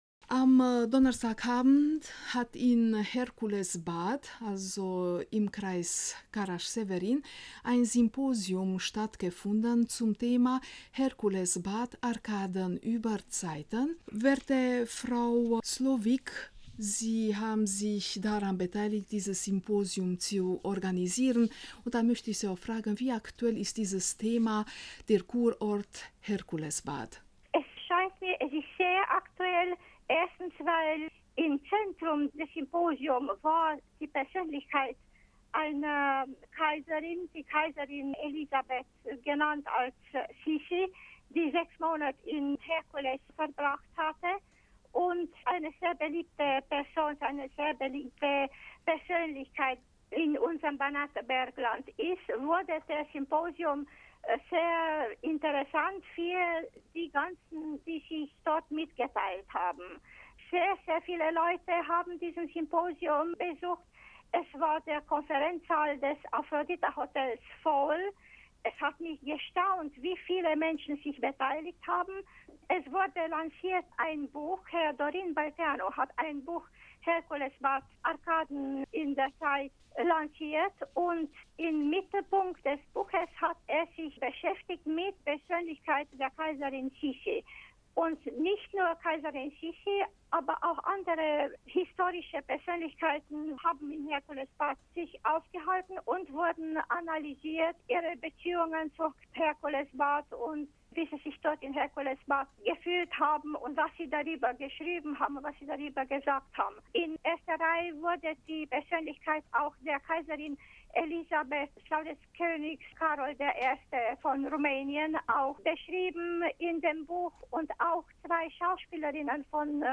Gepostet am Jun 12, 2017 in Gesellschaft, Z_Radio Bukarest Inlandsdienst